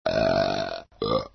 Burps ringtone download
File Name: Burps.mp3